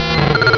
Cri de Loupio dans Pokémon Rubis et Saphir.